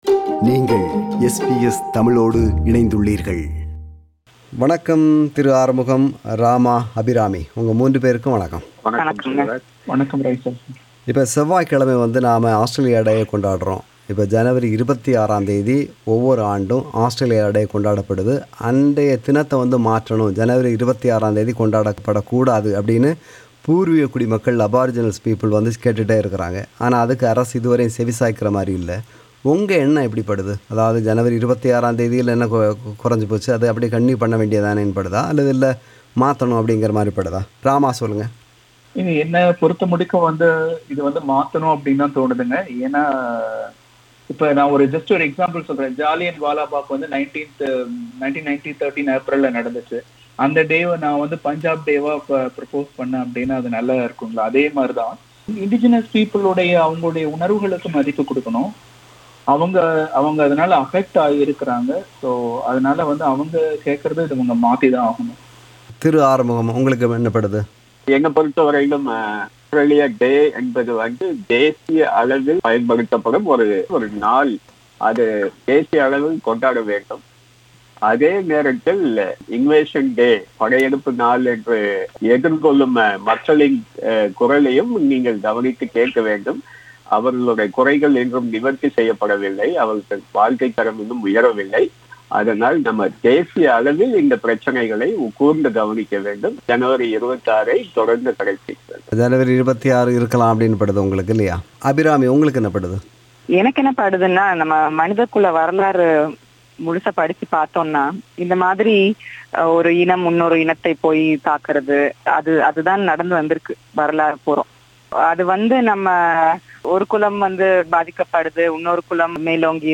ஆஸ்திரேலிய தினம் செவ்வாய்க்கிழமை (ஜனவரி 26) கொண்டாடப்படும் வேளையில் நாடு சந்தித்த வெற்றிகள் மற்றும் தோல்விகள் அத்துடன் நாம் எதிர்கொள்ளும் சவால்கள் என்று பல அம்சங்கள் குறித்து கலந்துரையாடுகின்றனர்